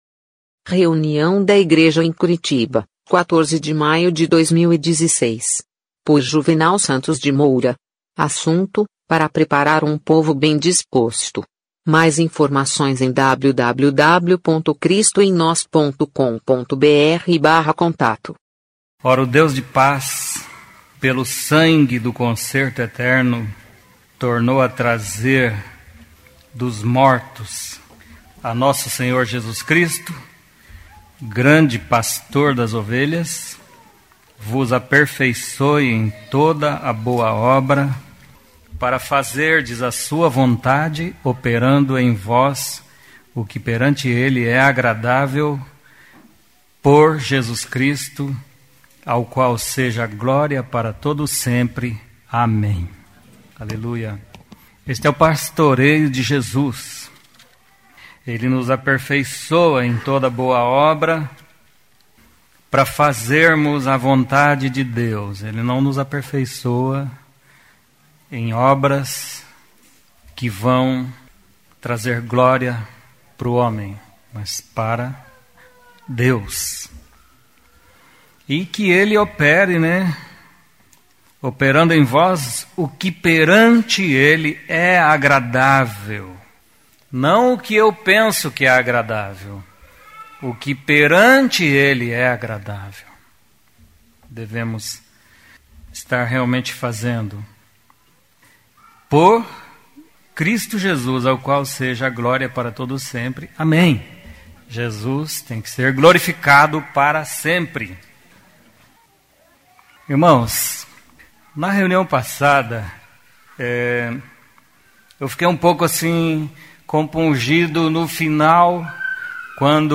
Mensagem
na reunião da igreja em Curitiba